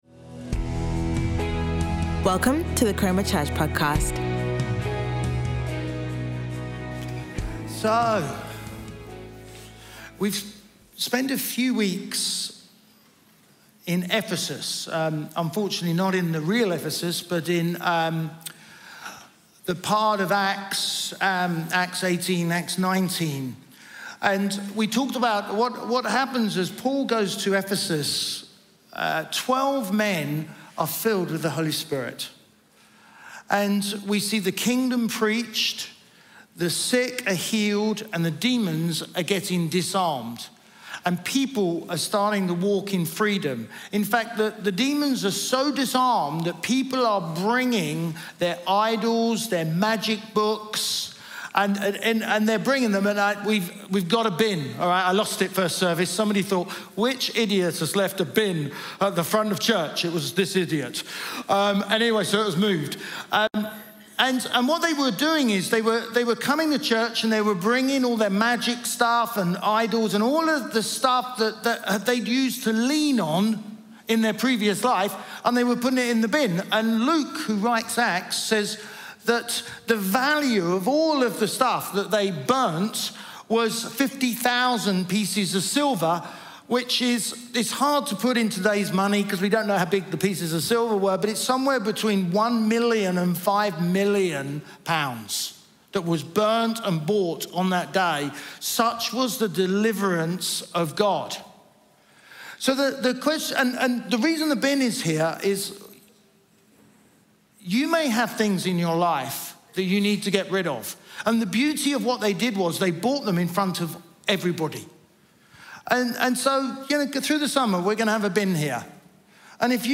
Chroma Church - Sunday Sermon Disrupting the Confusion